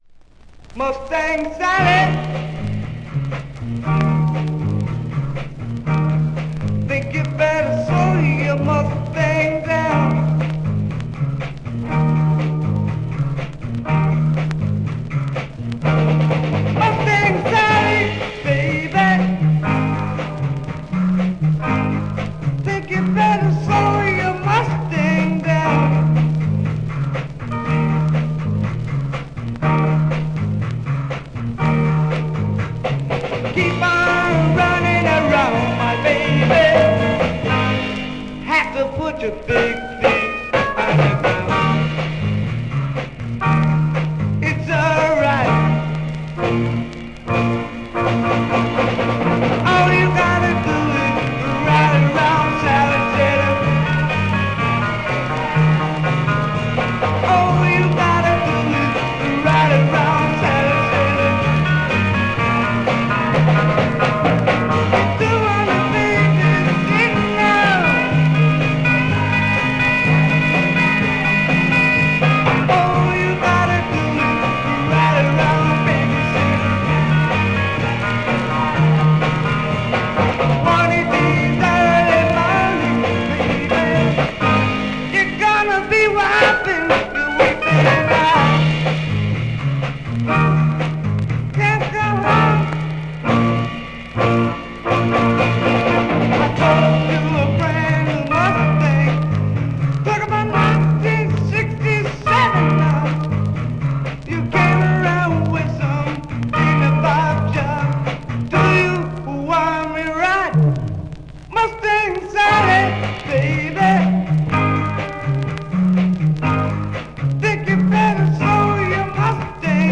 (unreleased acetate)